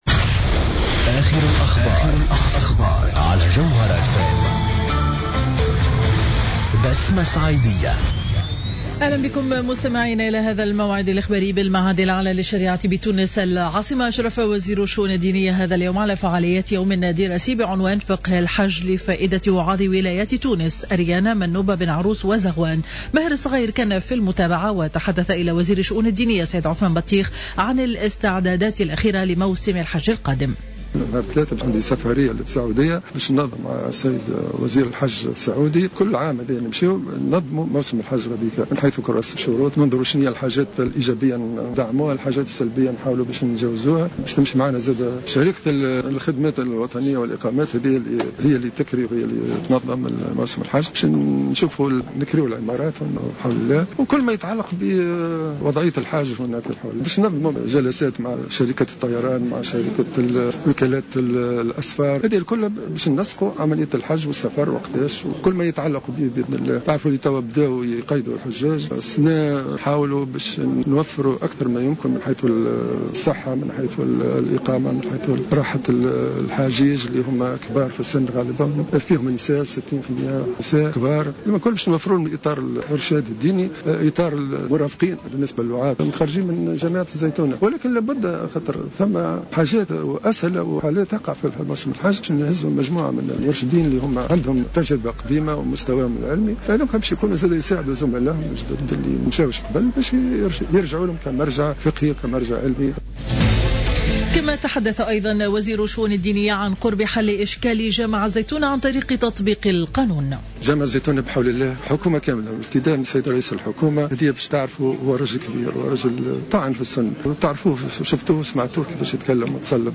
نشرة الأخبار منتصف النهار ليوم الاثنين 16 فيفري 2015